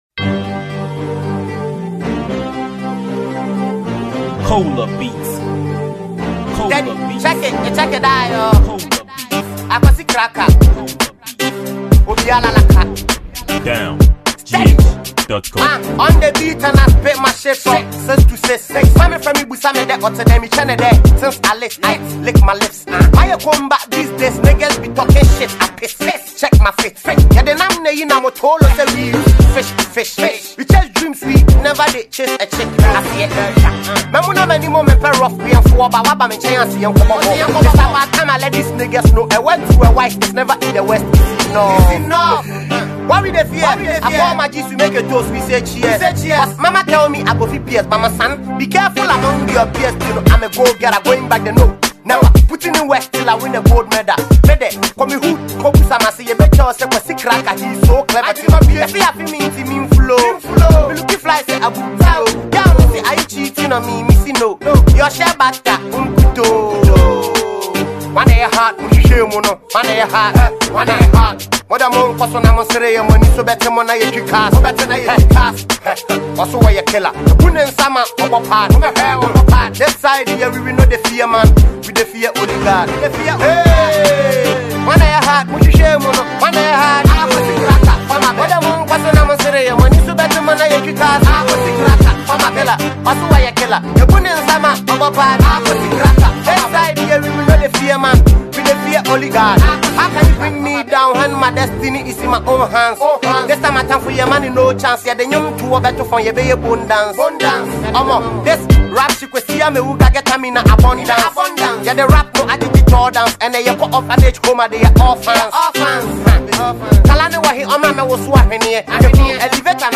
Genre: Hiplife/Hiphop